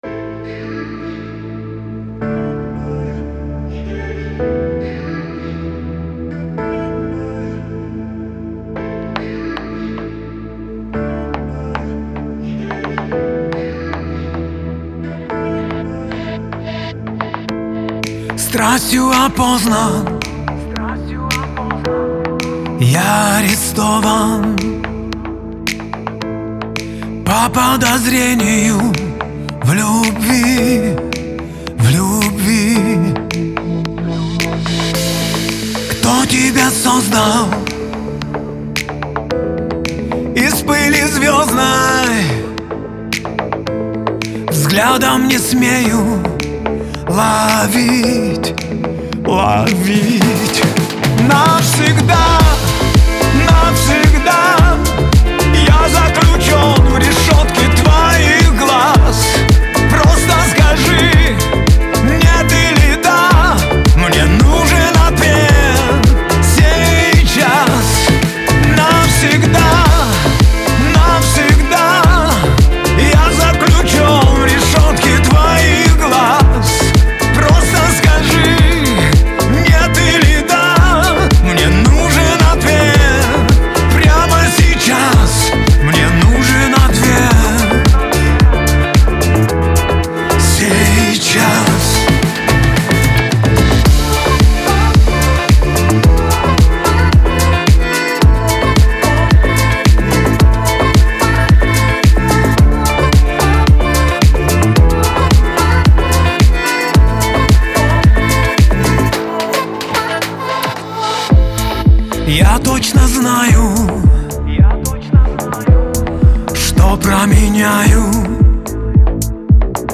эстрада
pop , грусть